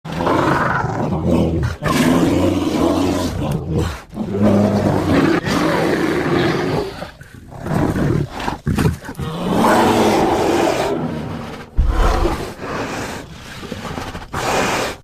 Звуки гризли
Грозный рык схватки два медведя прорычали друг на друга